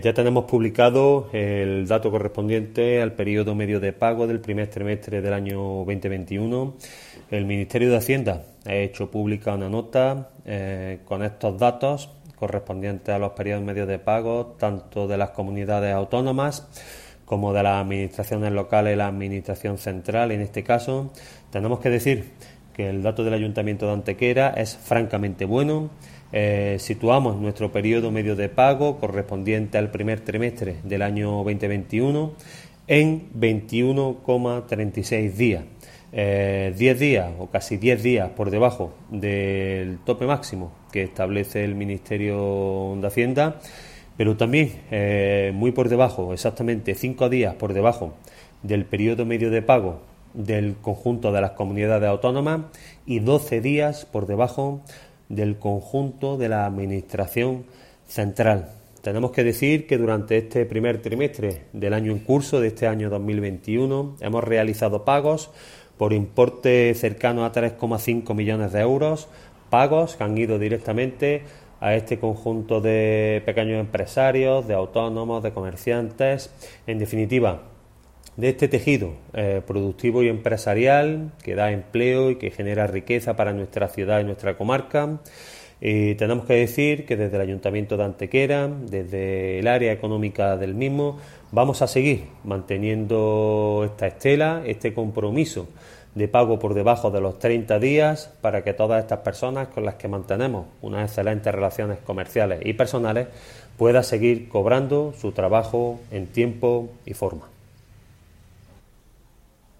Cortes de voz A. Garcia 858.37 kb Formato: mp3